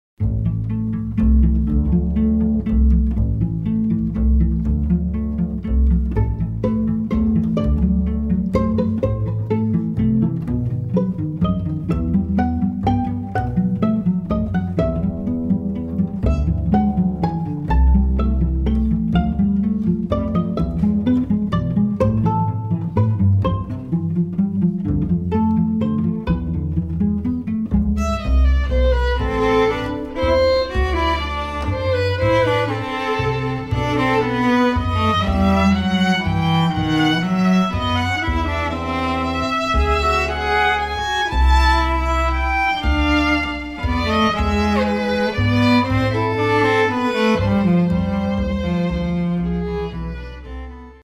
Bass
Fiddle
Cello
guitar